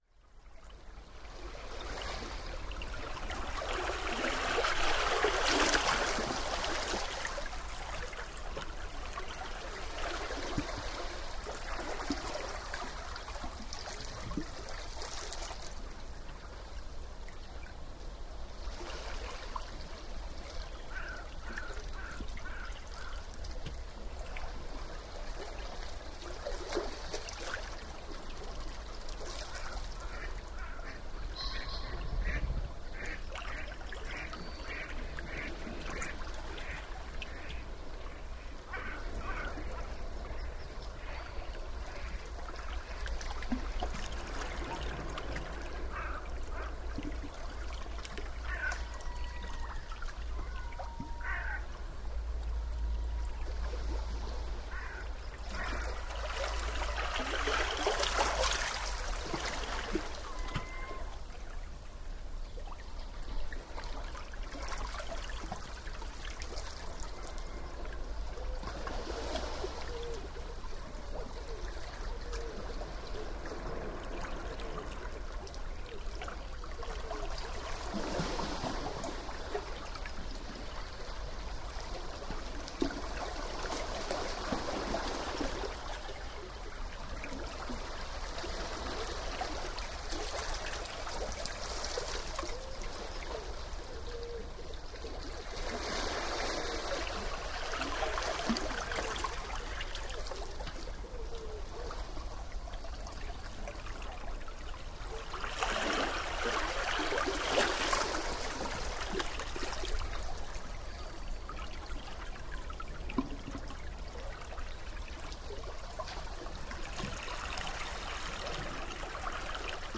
Water, surf, waves and sounds of nature.